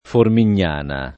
Formignana [ formin’n’ # na ]